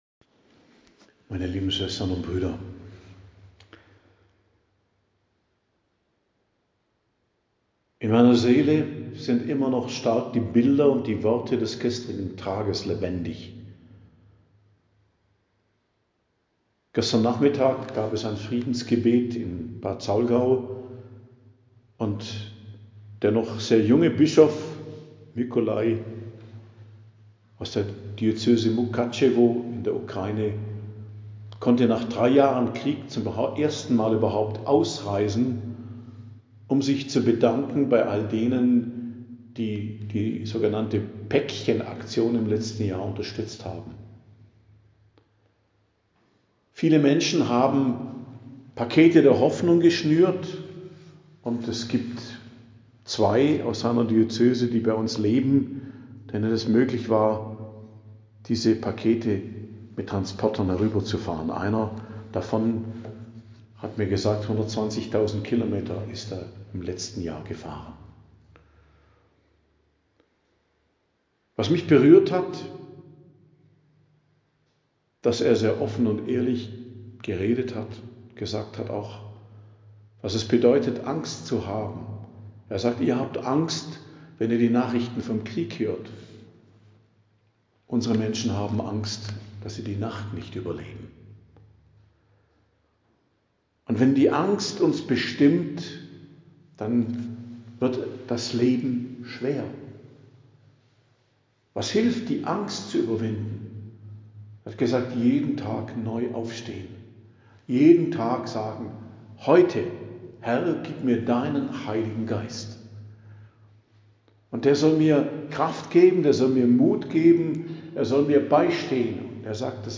Predigt am Donnerstag der 1. Woche der Fastenzeit, 13.03.2025